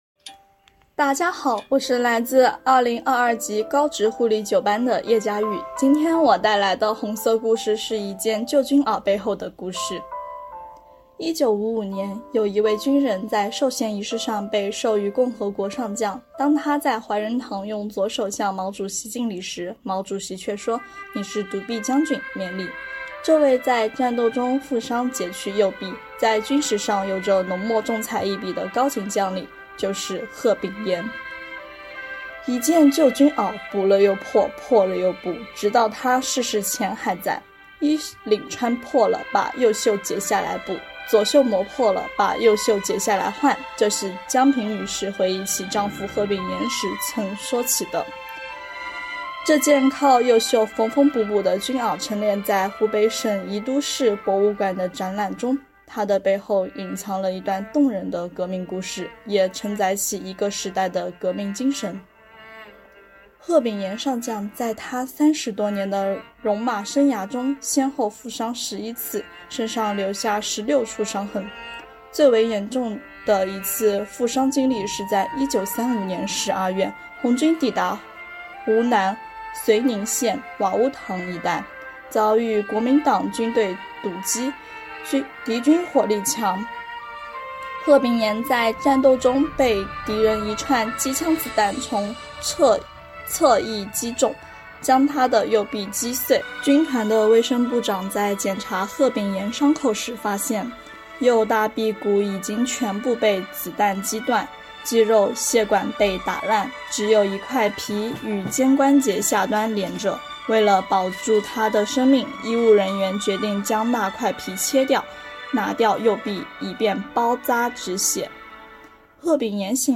朗读者